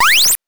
jump_9.wav